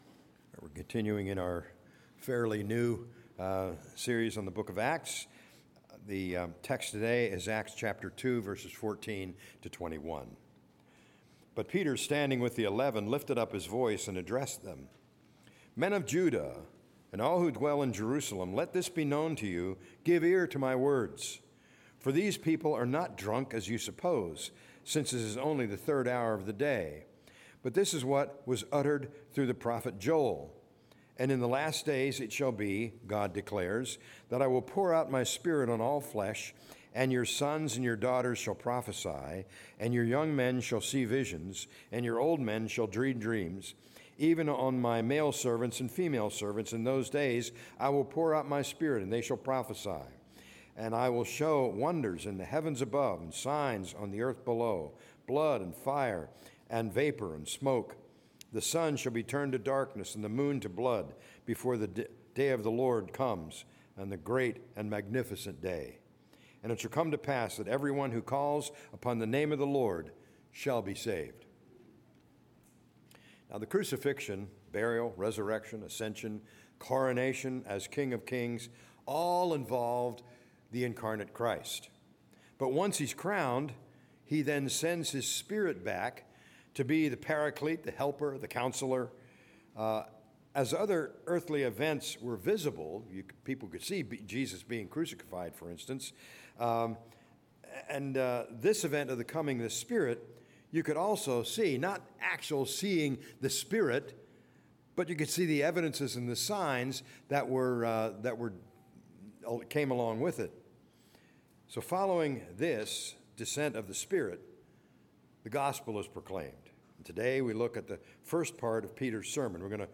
A message from the series "Acts 2025/26."